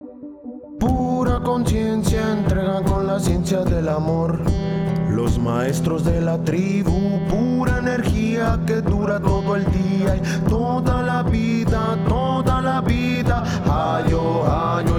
Single Chamánico